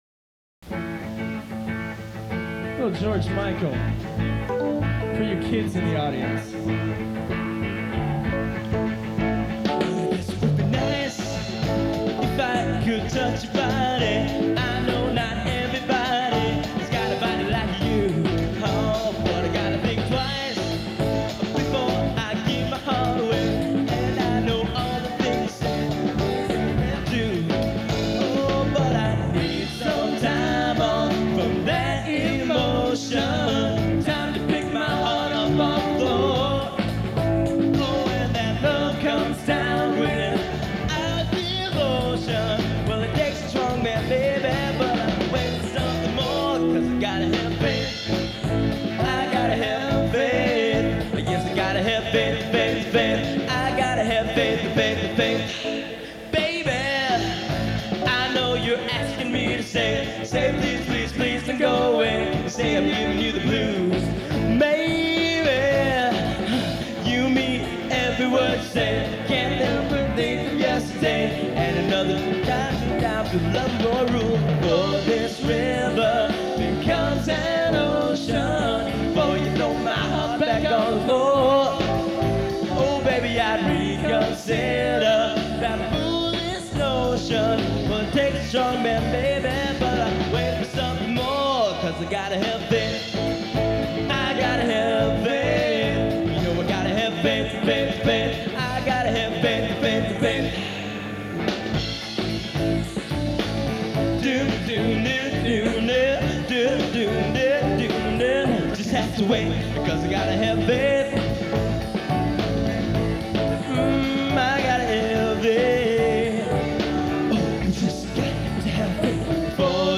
Category Rock/Pop
Studio/Live Live